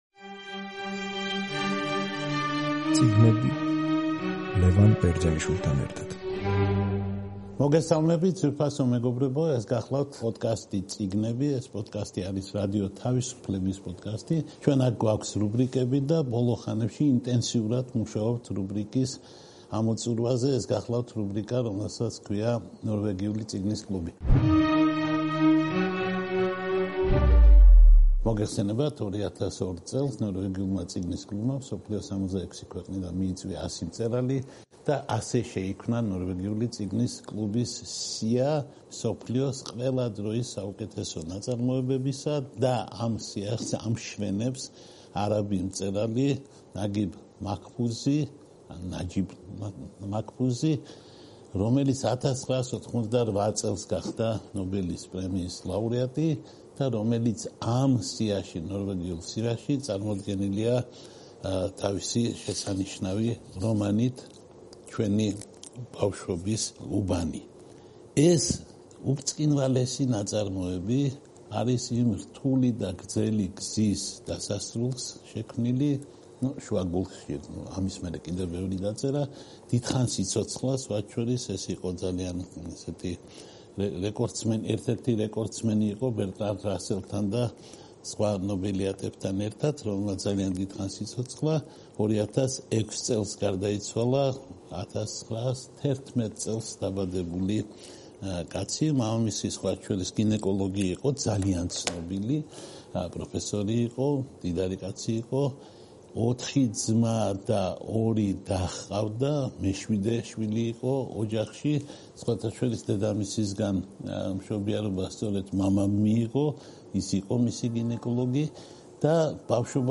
რადიო თავისუფლების პოდკასტი „წიგნები“ და მისი რუბრიკა „ნორვეგიული წიგნის კლუბი“ გთავაზობთ საუბარს დიდ ეგვიპტელ მწერალზე, 1988 წლის ნობელის პრემიის ლაუტეატზე ლიტერატურაში, ნაგიბ მაჰფუზსა და მის რომანზე „ჩვენი უბნის ბავშვები“.